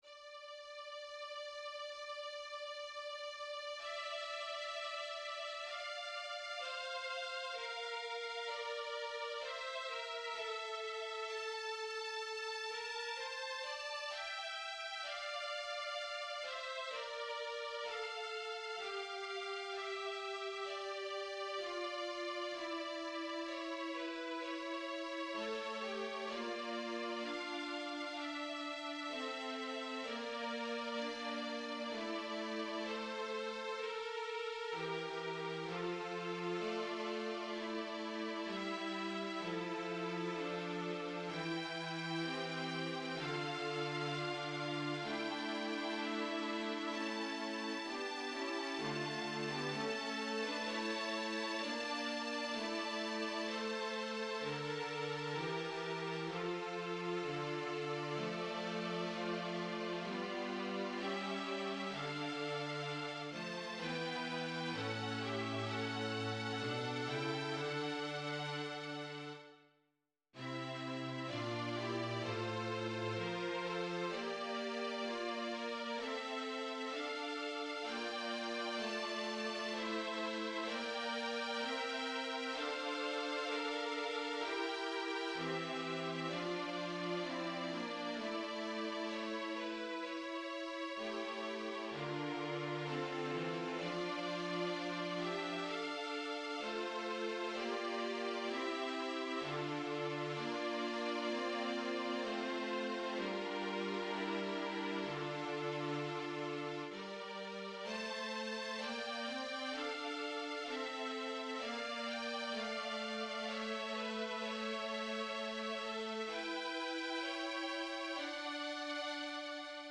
Voicing/Instrumentation: Orchestra